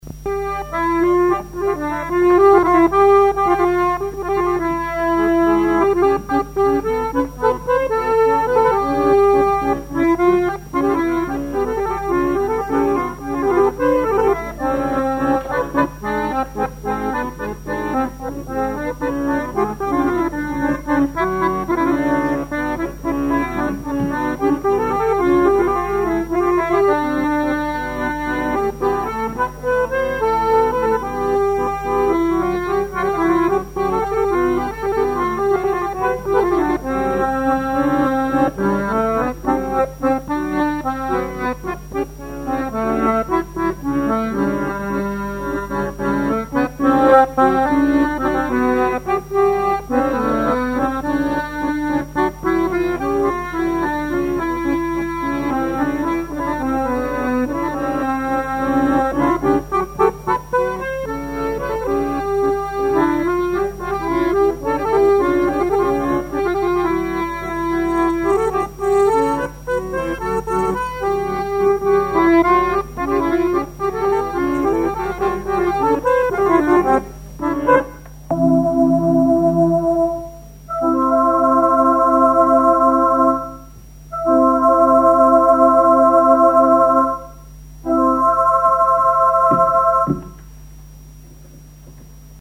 danse : valse
collectif de musiciens pour une animation à Sigournais
Pièce musicale inédite